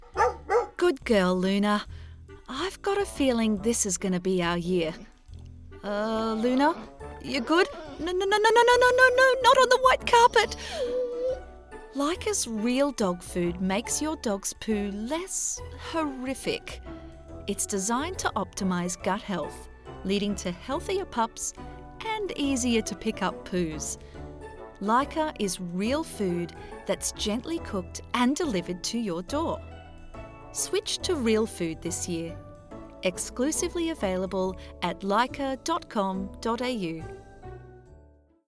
Female
English (Australian)
Adult (30-50)
Corporate
Dog Food Commercial
Words that describe my voice are Colourful, Friendly, Sincere.